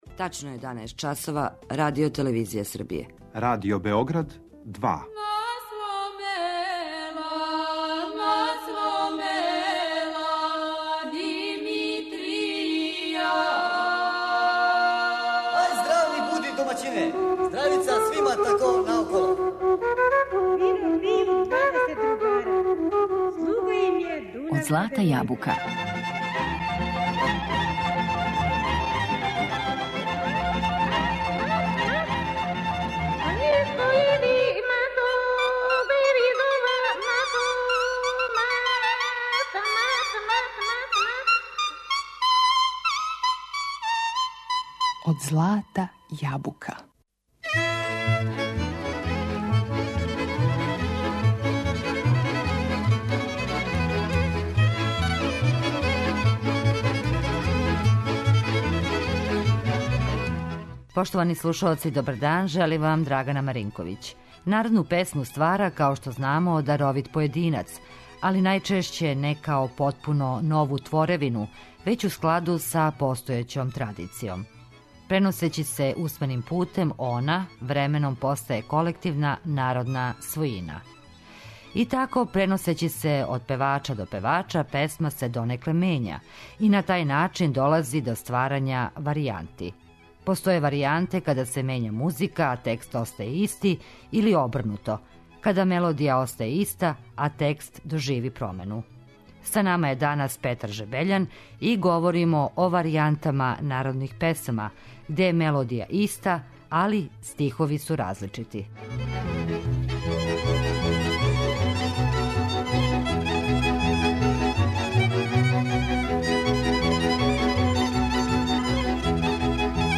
Данас говоримо и слушамо примере варијанти народних песама, где је мелодија остала иста али су стихови различити.